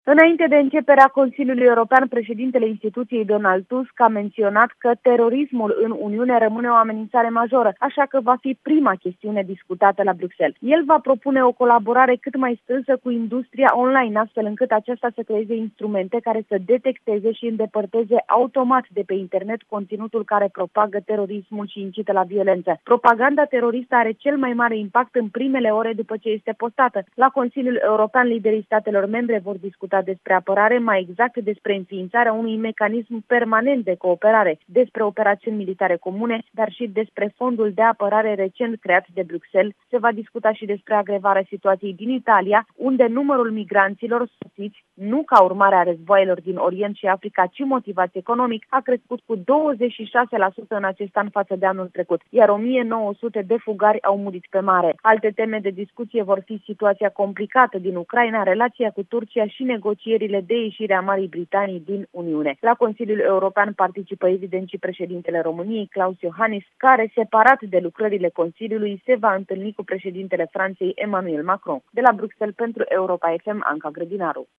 România e reprezentată de președintele Klaus Iohannis, care, după cum relatează trimisul special Europa FM la Bruxelles